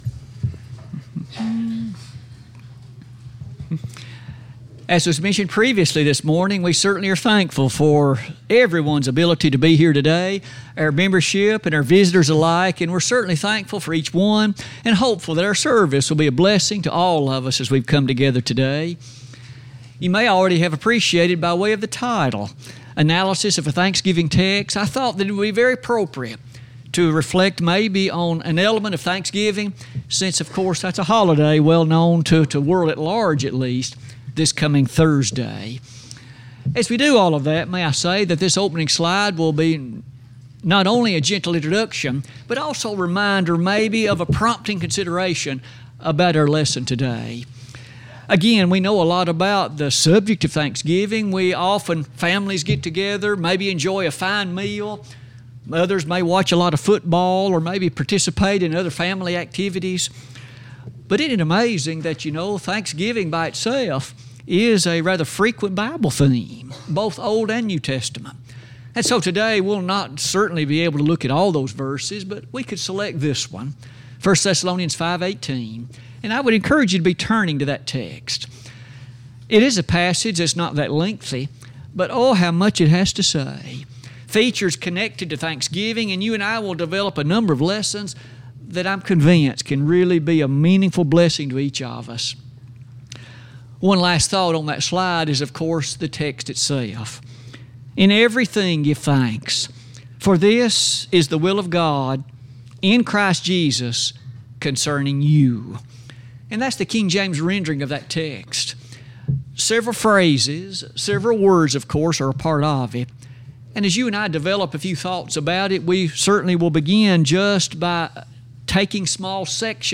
1 Advent-ture- Youth Sunday Panel Conversation 26:54